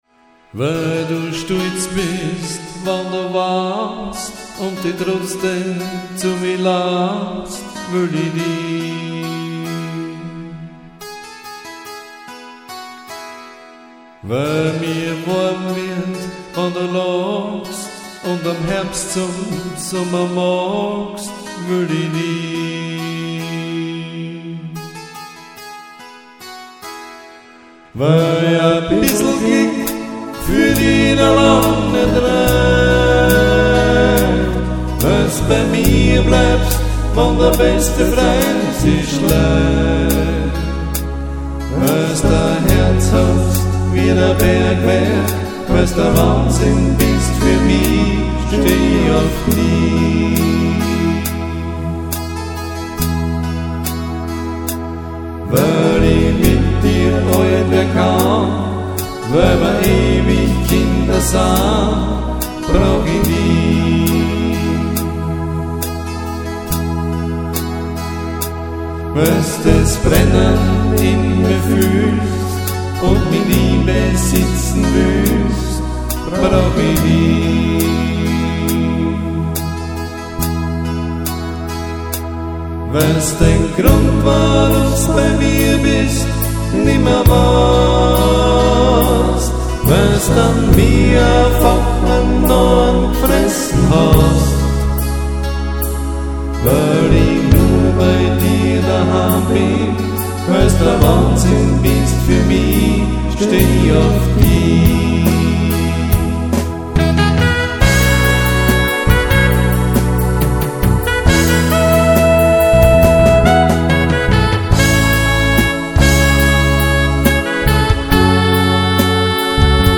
Demo CDs und Demo Songs
Sie wurden alle mit der AW 4416 Workstation aufgenommen und fertig gemischt.